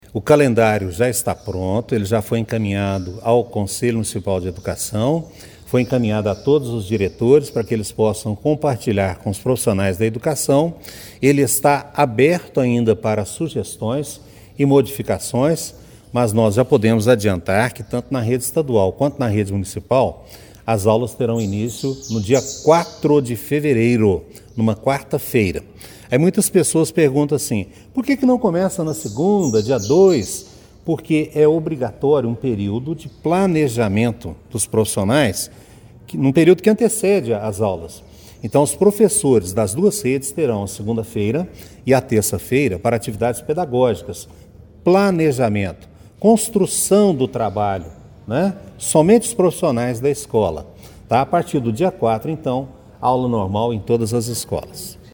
Nesta terça-feira (20), durante coletiva de imprensa no CMEI Cônego Gabriel Hugo da Costa Bittencourt, o secretário Marcos Aurélio dos Santos detalhou as ações que preparam as 34 escolas da cidade para o retorno das atividades.